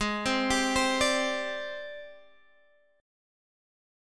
G#M7b5 chord